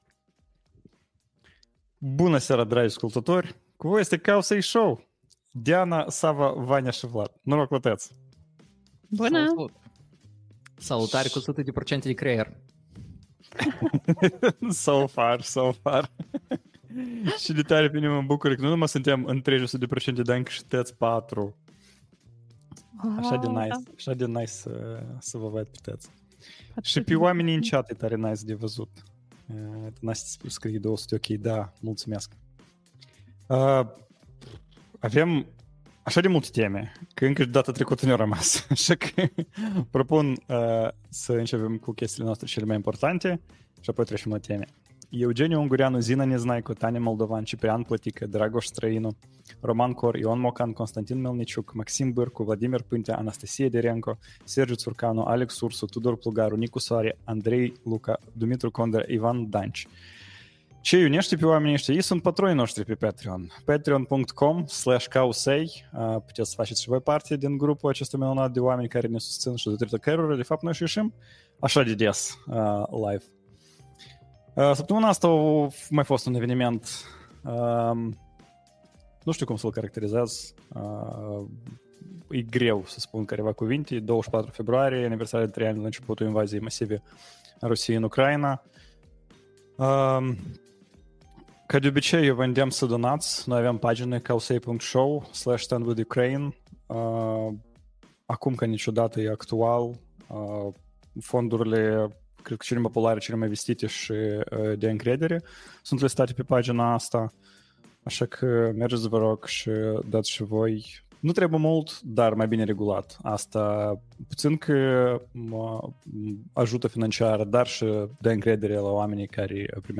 Live #136: Claude 3.7 Sonnet, Sweatshop AI, UK 1:0 Apple February 27th, 2025 Live-ul săptămânal Cowsay Show.